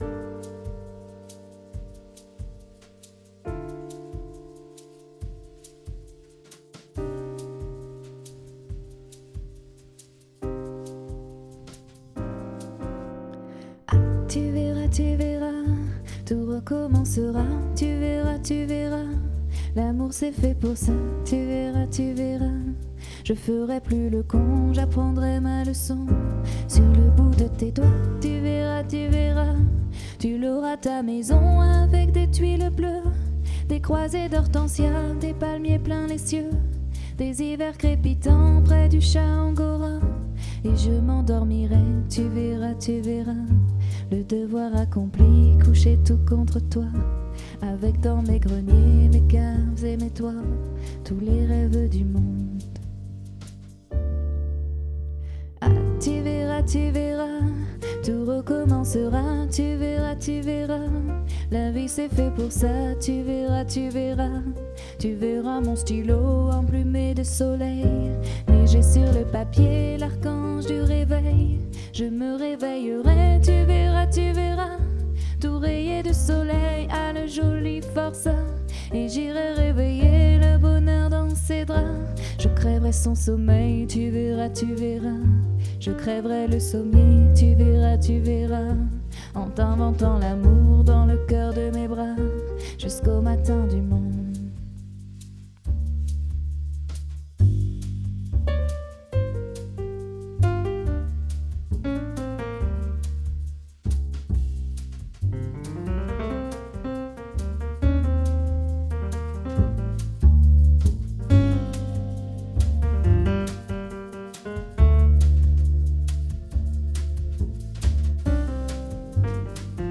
Un duo Piano-Voix au répertoire jazz et variété